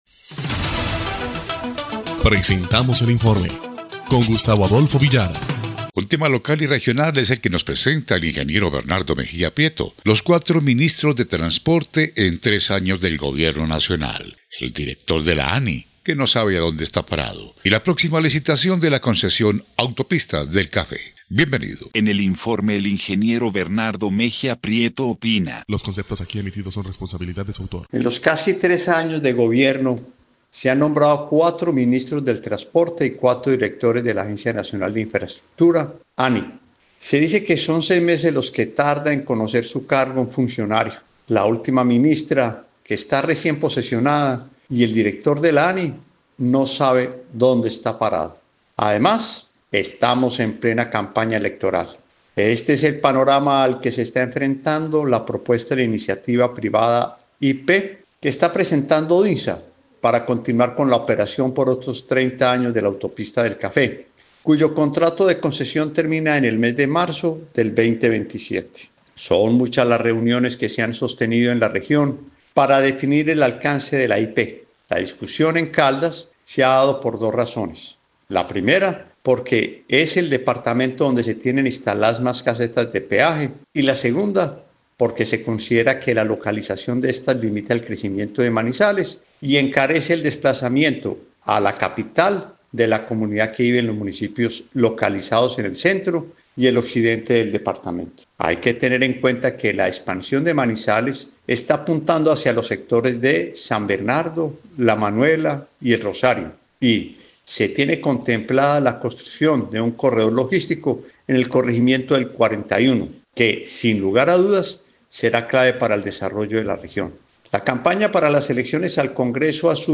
EL INFORME 1° Clip de Noticias del 14 de abril de 2025